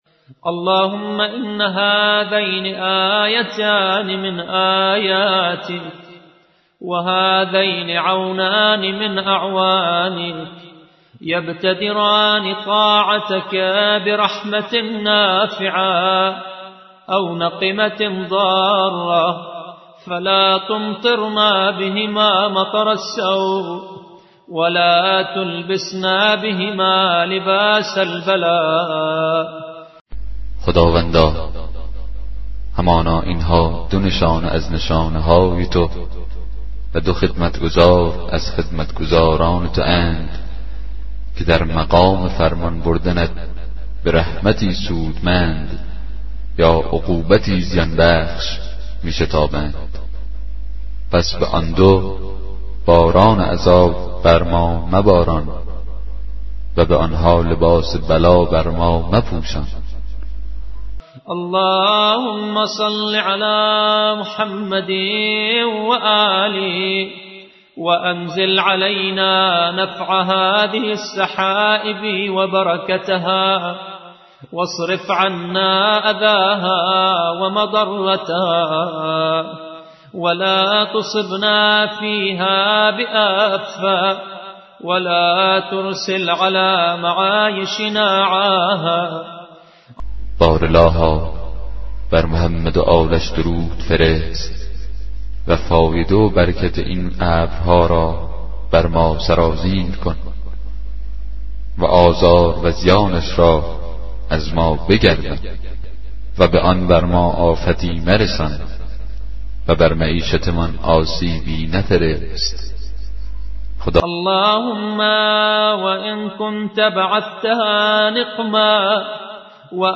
کتاب صوتی دعای 36 صحیفه سجادیه